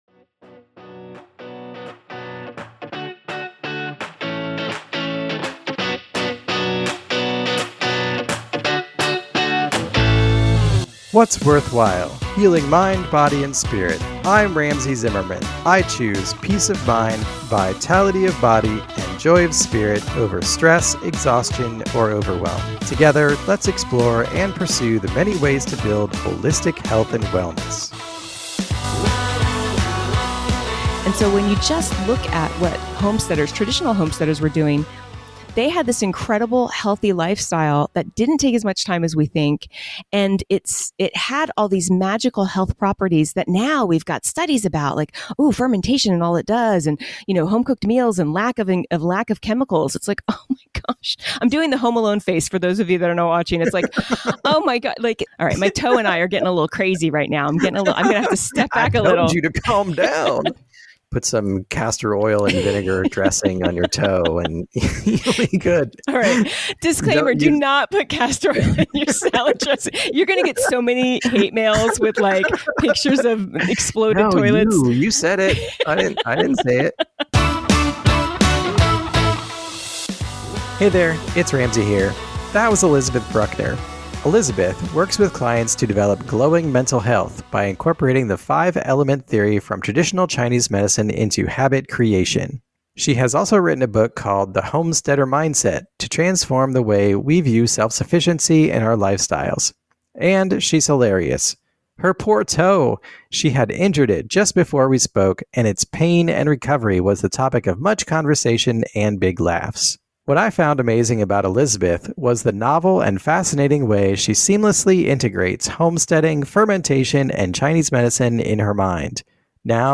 This is a fast-paced, surprisingly funny episode.